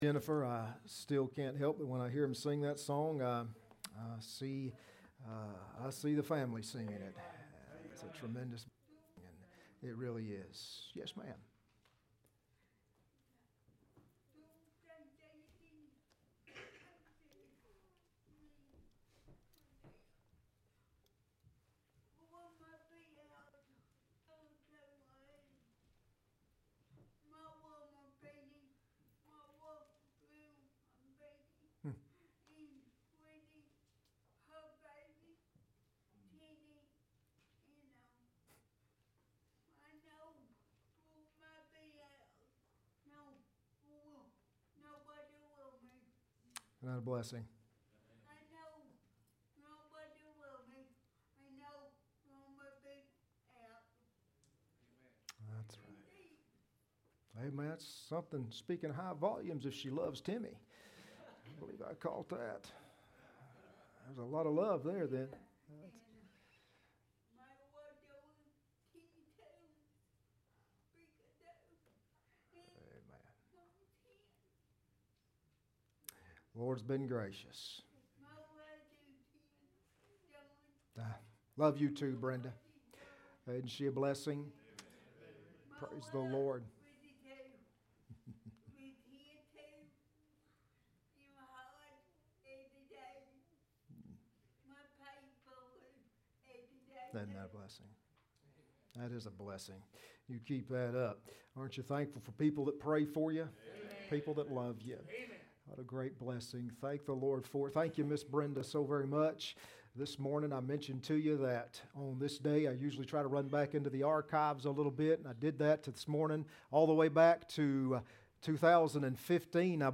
Mathew 5:41 Service Type: Sunday Evening Next Sermon